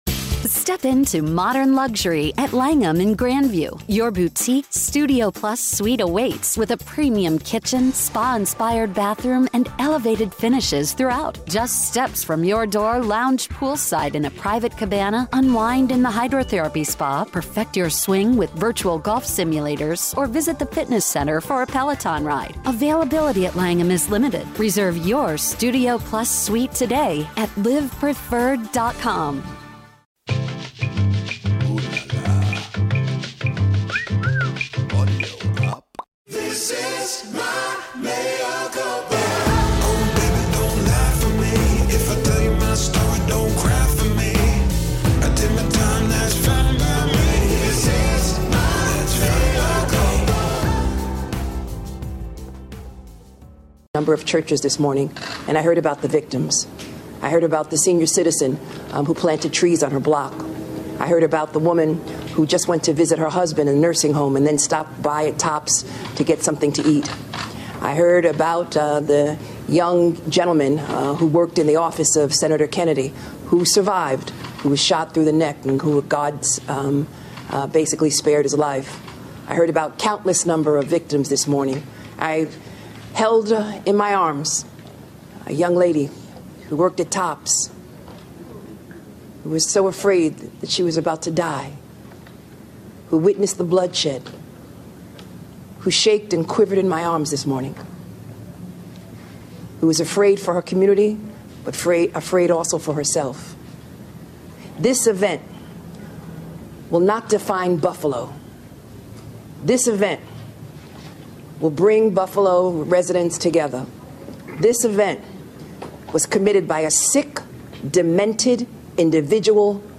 Replacement Theory Explained + A Conversation with David Corn